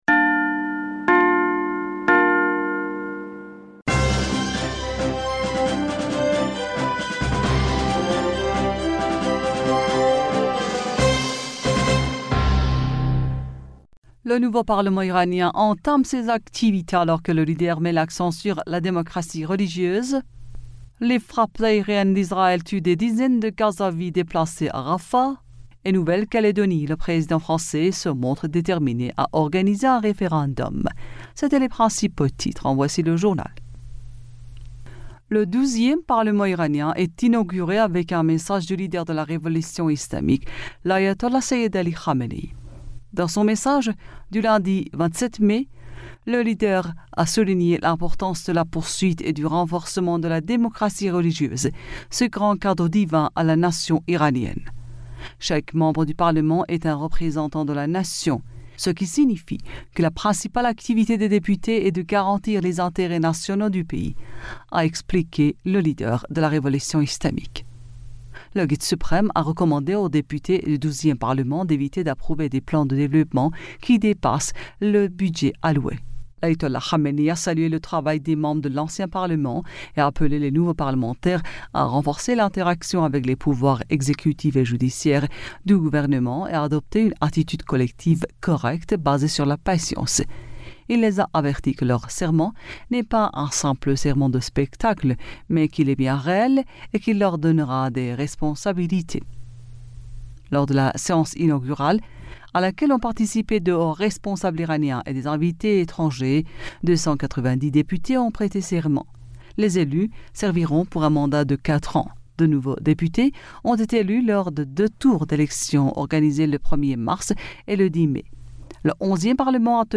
Bulletin d'information du 27 Mai